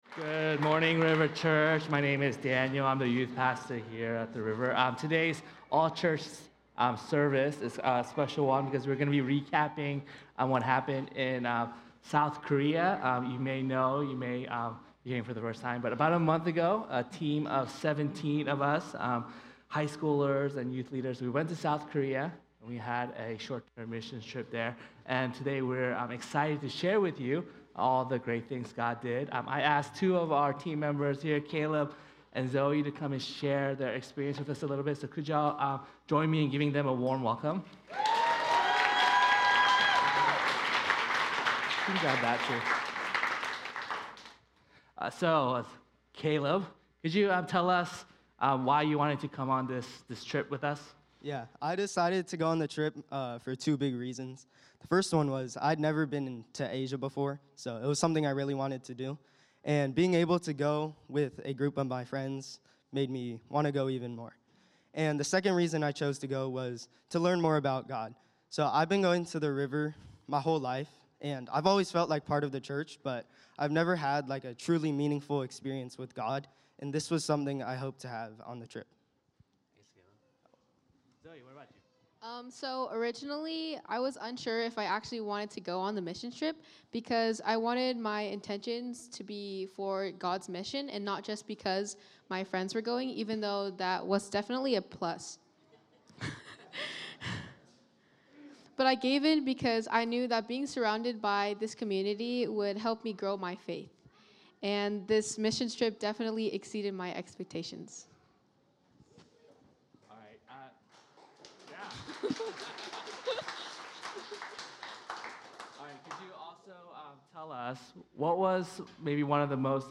The River Church Community Sermons